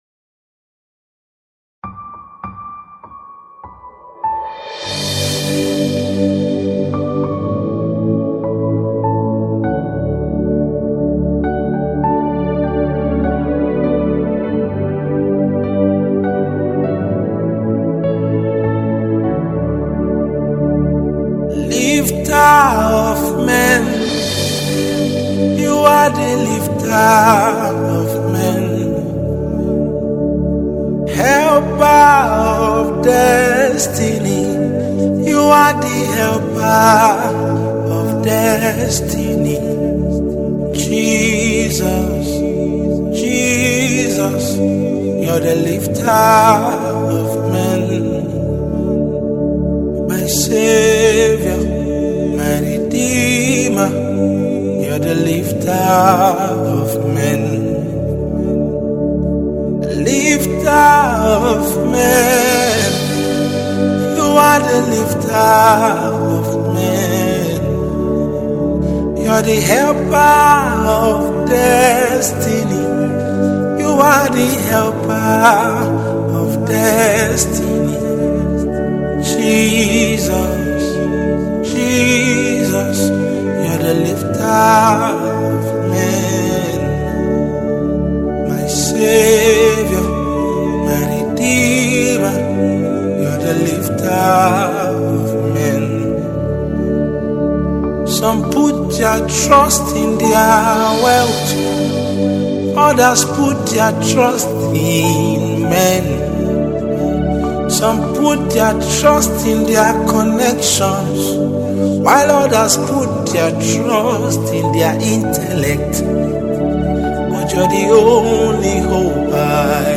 Nigeria Gospel Music
powerful worship song